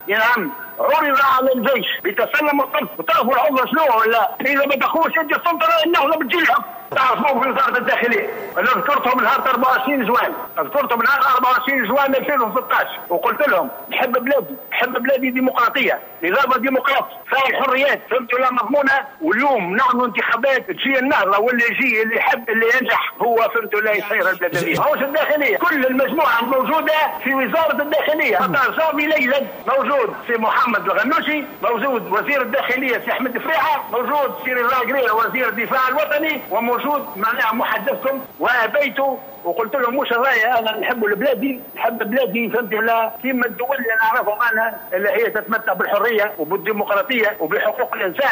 Le général Rachid Ammar, a indiqué ce jeudi 5 novembre 2015, lors de son intervention dans l'émission "le 8ème jour", diffusée sur la chaîne Elhiwar Ettounsi, que le pouvoir a été proposé à l'Armée le 14 janvier 2011.